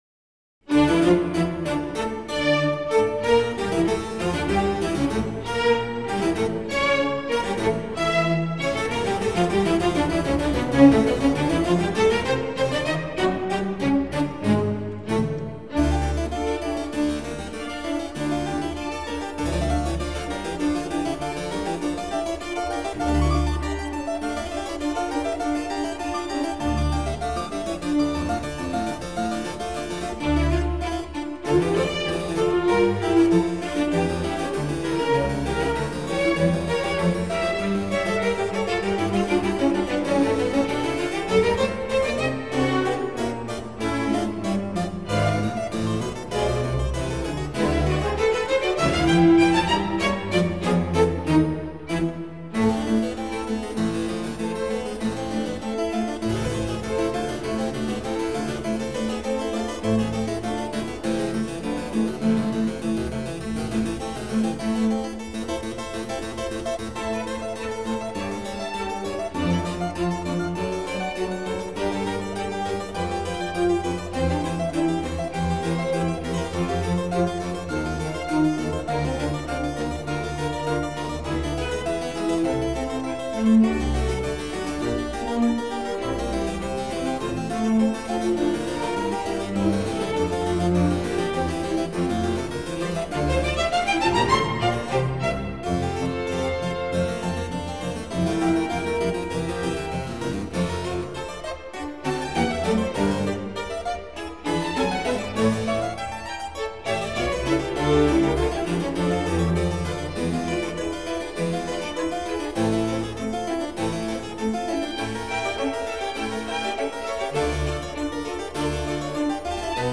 شور و هیجان در این قطعه از باخ موج میزنه: